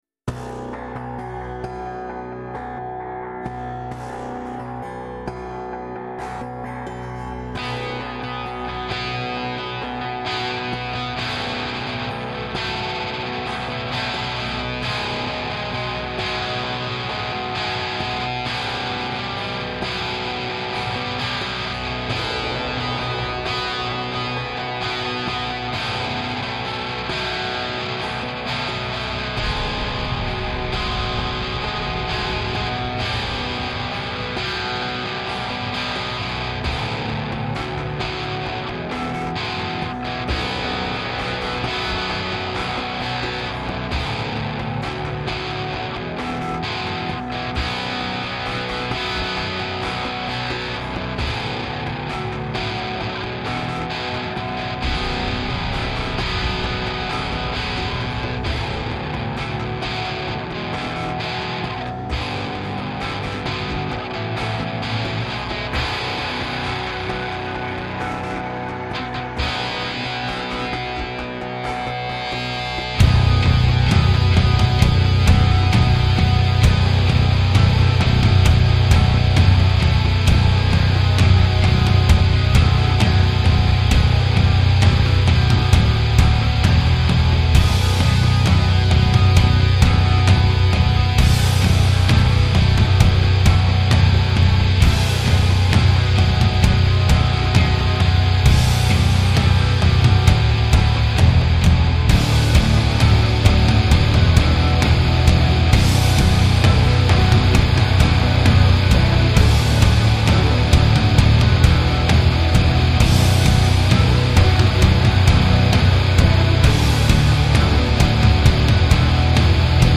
Жанр: Industrial, Alternative